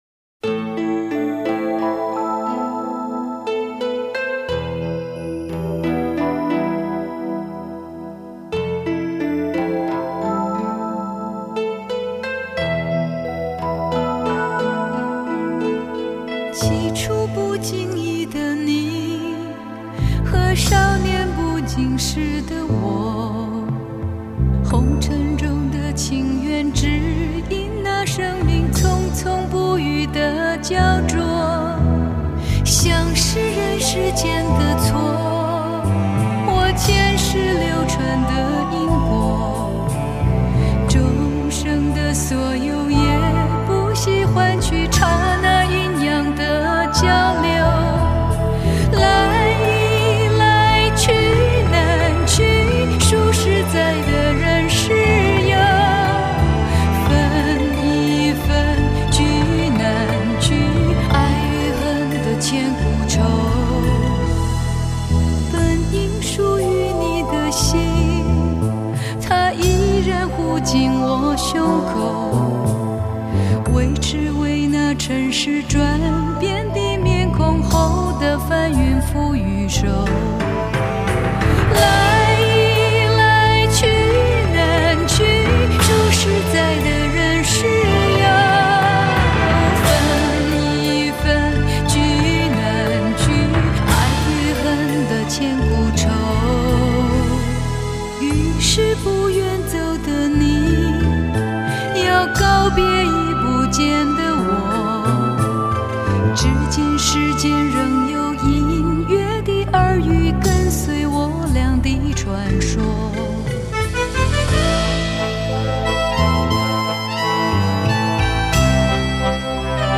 唱片公司并以HDCD进行重新编码处理，音质表现更上一层楼。